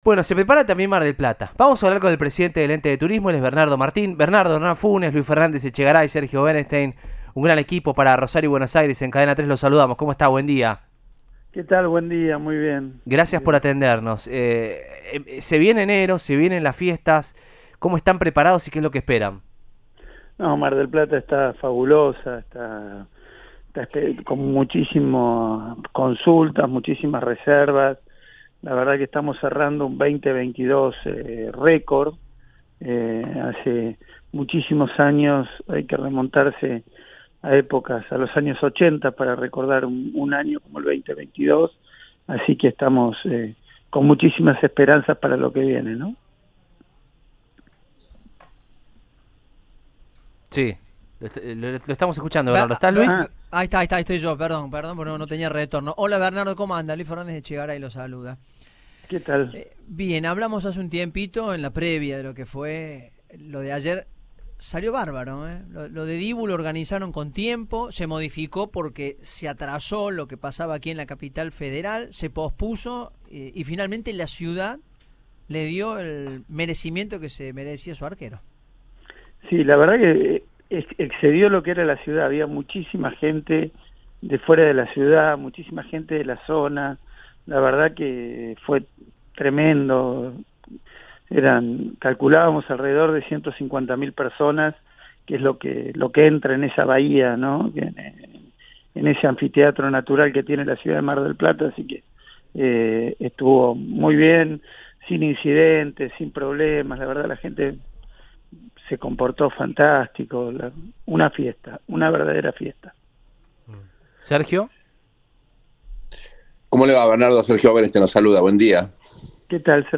Así lo confirmó Bernardo Martín, director del Ente de Turismo de Mar del Plata a Cadena 3 Rosario. Esperan cifras récord de turistas.